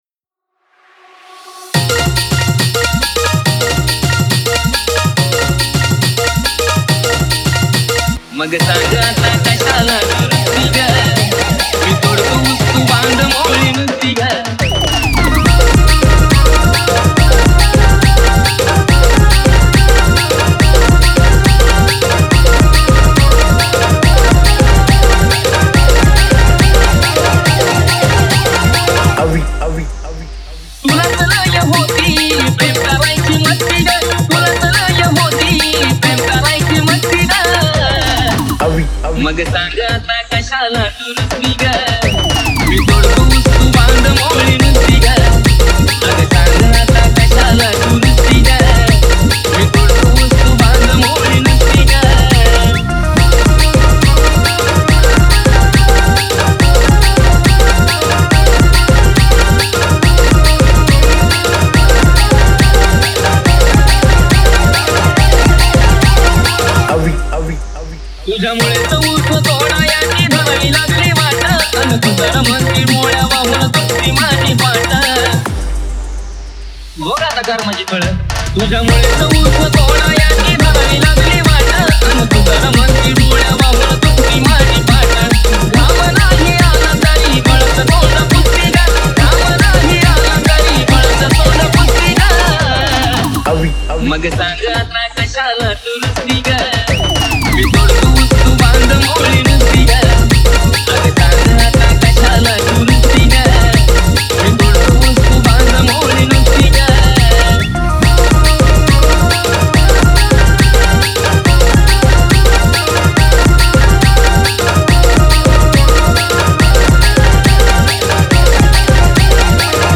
Marathi Dj Single 2025